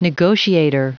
Prononciation du mot negotiator en anglais (fichier audio)
negotiator.wav